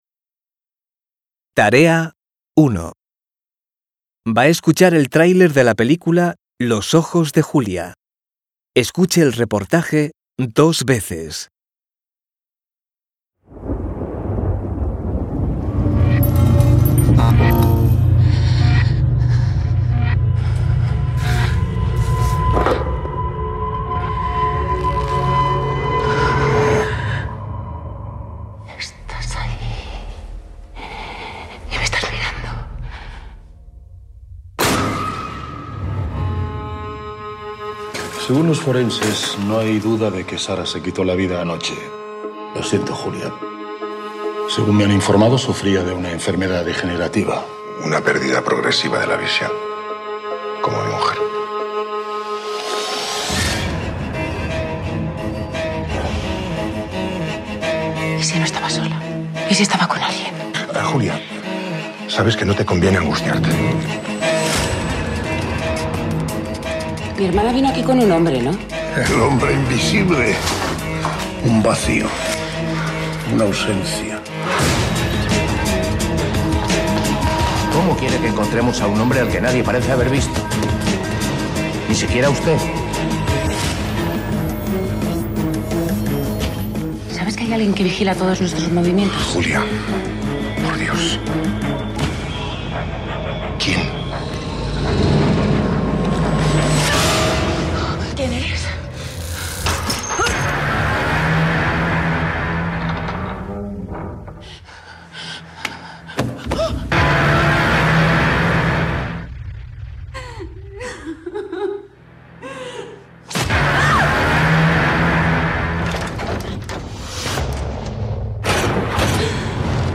Después de escuchar el tráiler de la película, escriba un correo electrónico al cine organizador del evento para entrar en el sorteo.